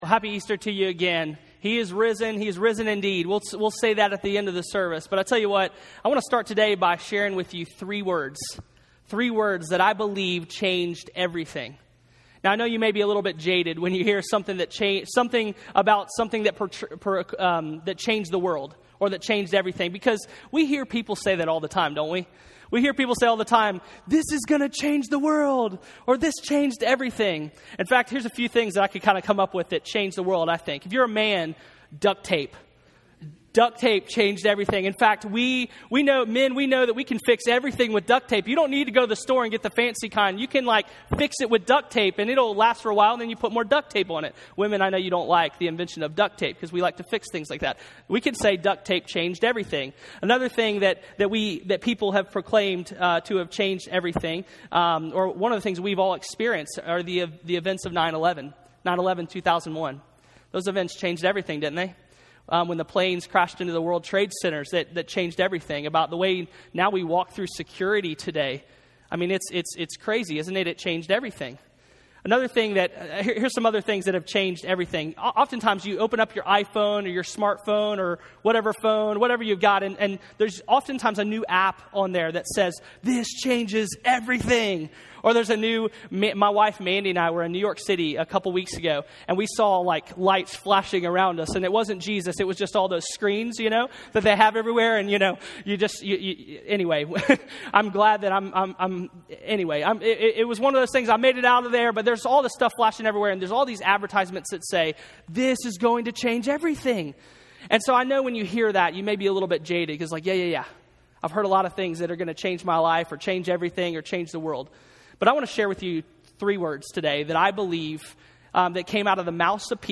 SERMON SERIES Easter at The Fountain 2014 The cross and Easter is all about God's love offered for us.